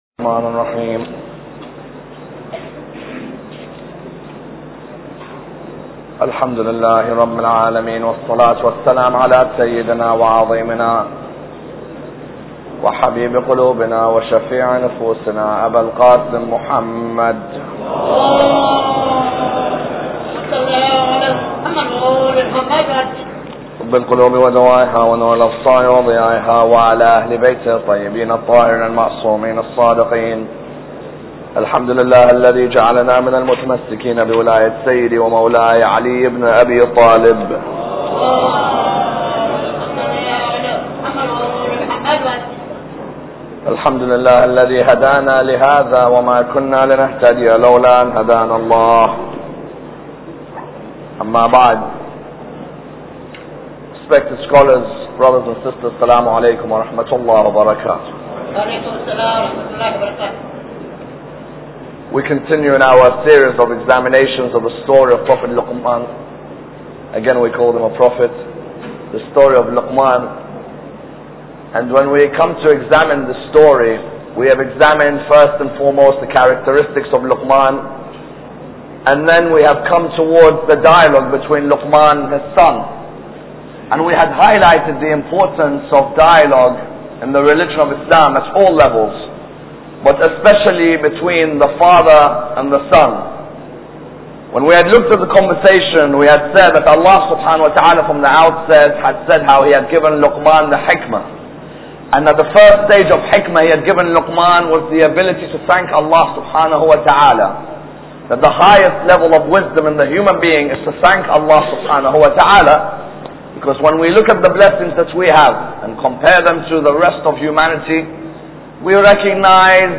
Lecture 9